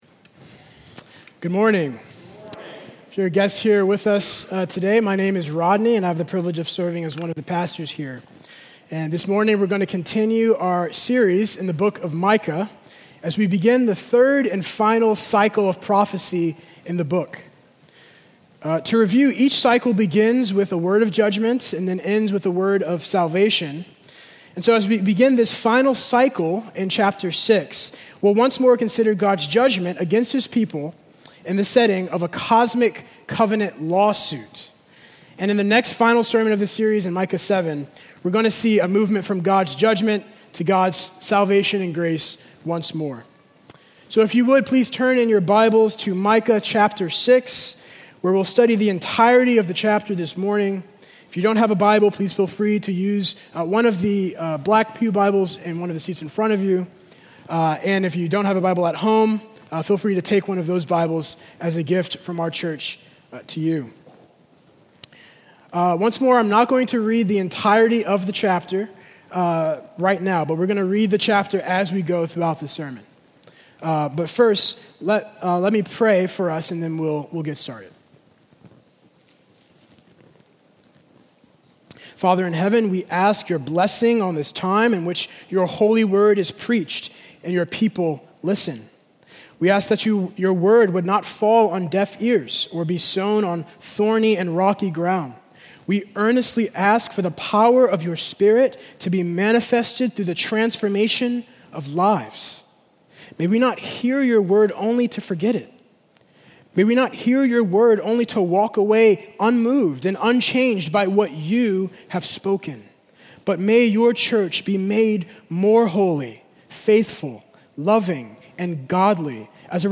Sermon-914.mp3